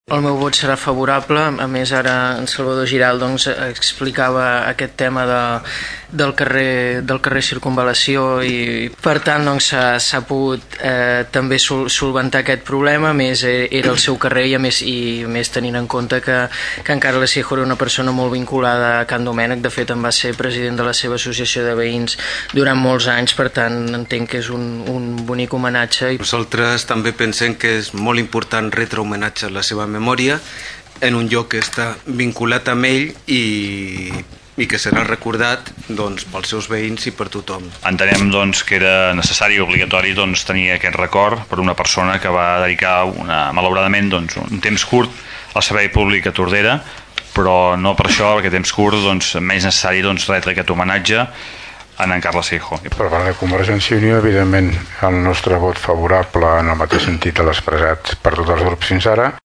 Els partits polítics en representació municipal van aplaudir la iniciativa. Escoltem Xavier Martin (PP), Rafa Delgado (PSC), Xavier Pla (ERC+Gent Tordera) i Joan Carles Garcia (CiU).